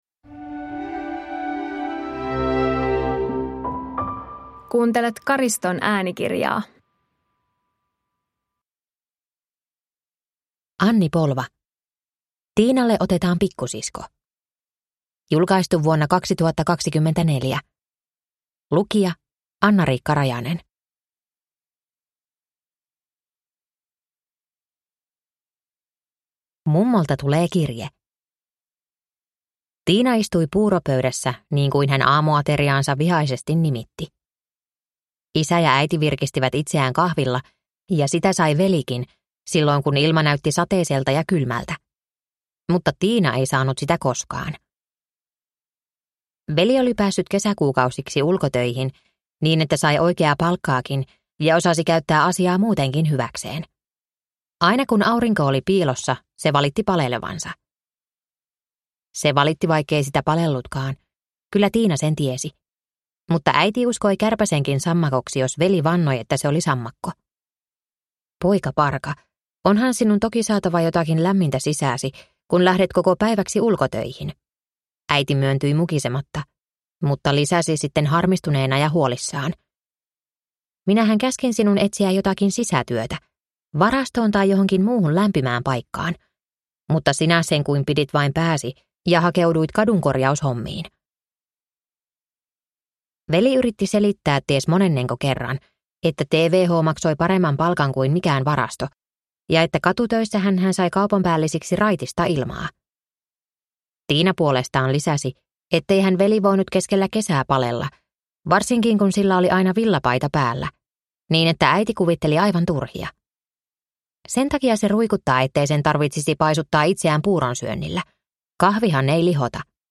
Tiinalle otetaan pikkusisko (ljudbok) av Anni Polva